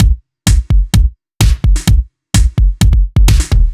Index of /musicradar/french-house-chillout-samples/128bpm/Beats
FHC_BeatA_128-02_KickSnare.wav